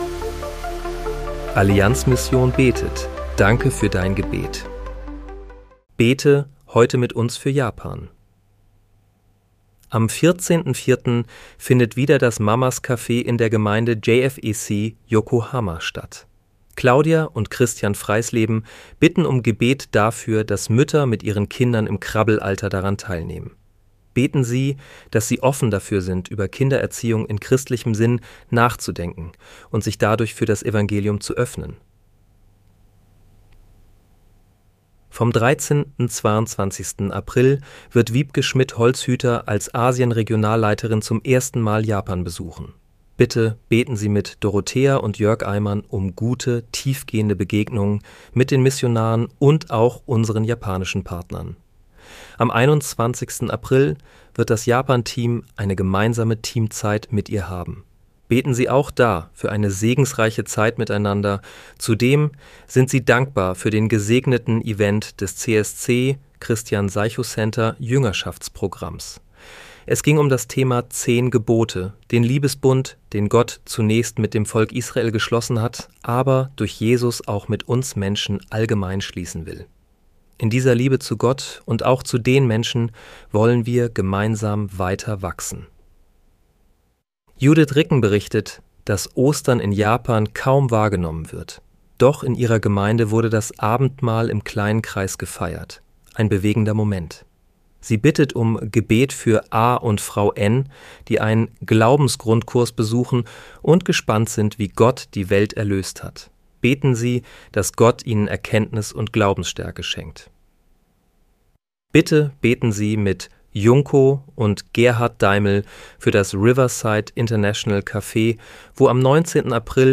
Bete am 18. April 2026 mit uns für Japan. (KI-generiert mit der